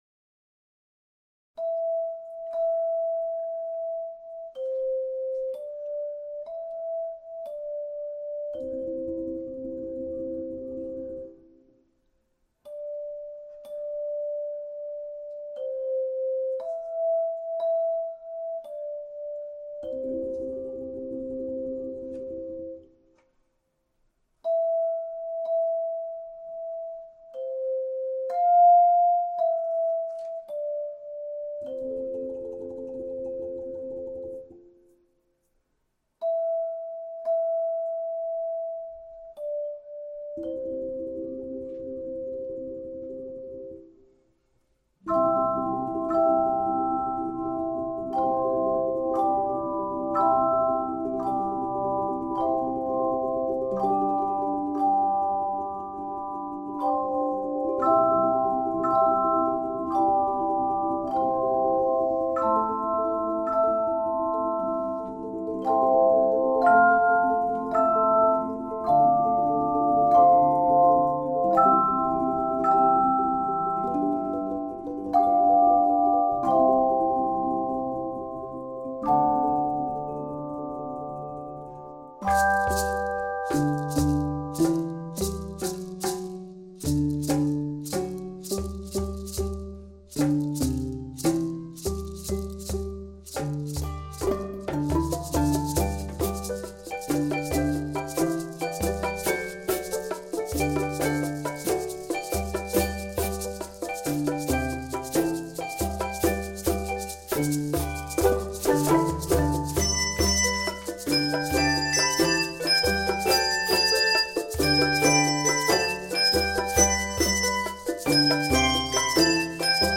and is a joyful, upbeat original composition